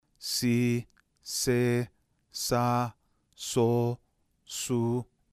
Las secuencias [si se sa so su].